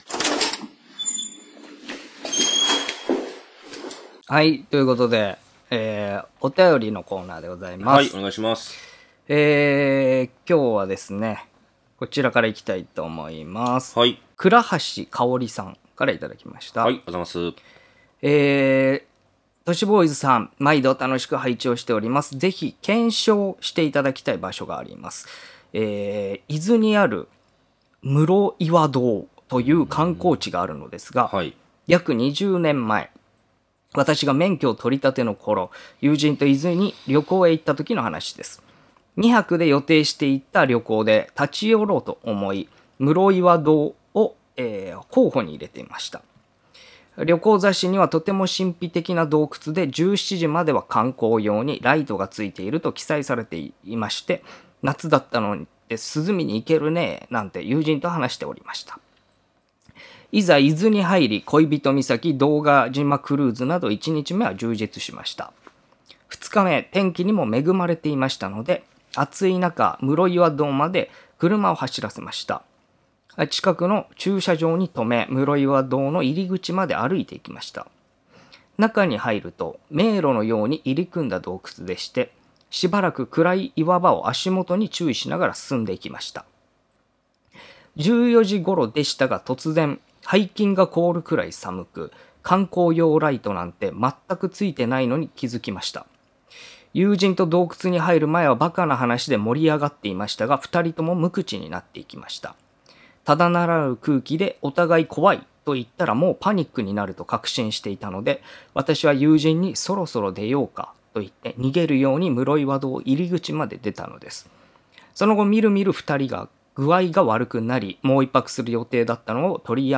若手放送作家の２人が都市伝説や日常に忍び寄るオカルト又は眉唾な噂話を独自の目線で切りお送りしていく番組です。